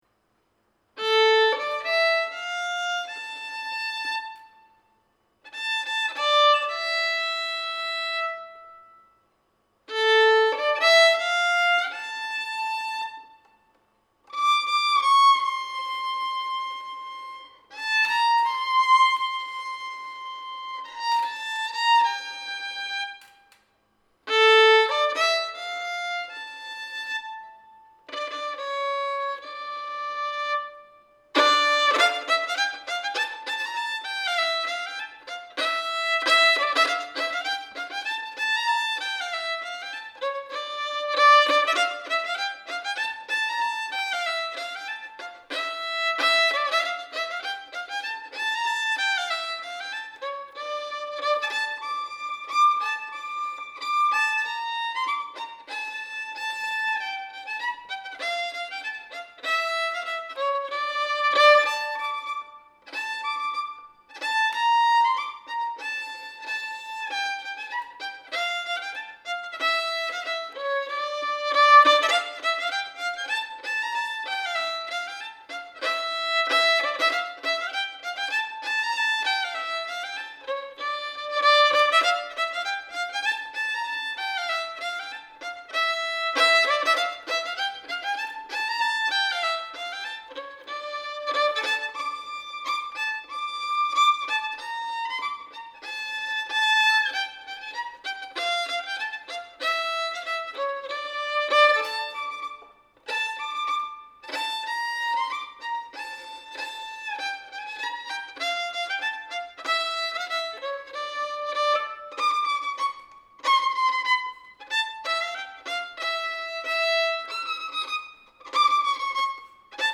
D Minor